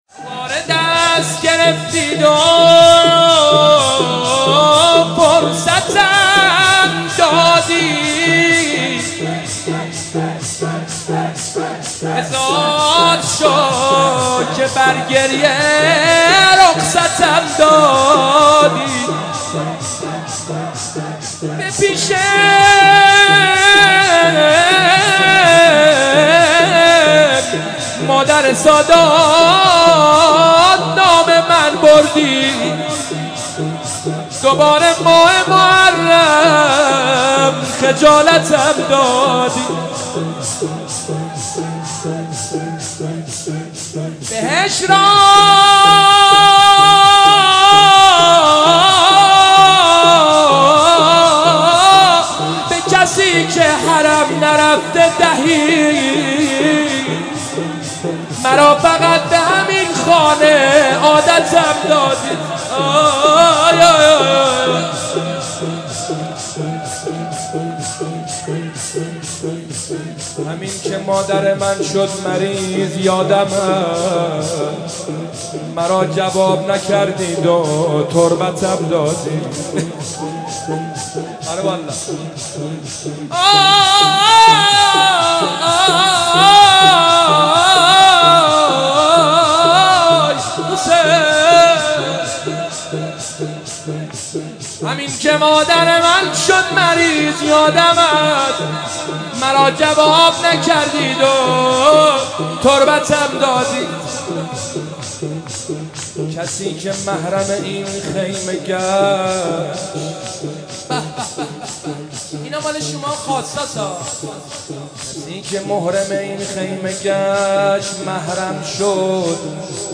مراسم شب اول محرم 94